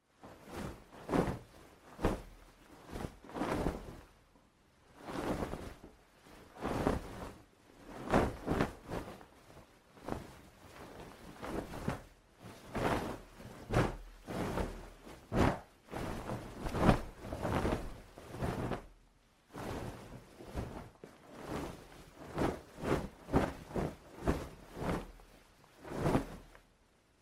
Tiếng Đắp chăn, Sửa soạn chăn mền… sột soạt
Thể loại: Tiếng động
Description: Tiếng đắp chăn, kéo mền, trải chăn, phủ ga… vang lên nhẹ nhàng nhưng rõ rệt, với âm “sột soạt”, “xào xạc”, “lạo xạo” của từng thớ vải cọ sát. Khi đôi tay vuốt phẳng các nếp gấp, chỉnh mép chăn, kéo mền ôm trọn cơ thể, âm thanh dày và êm vang lên...
tieng-dap-chan-sua-soan-chan-men-sot-soat-www_tiengdong_com.mp3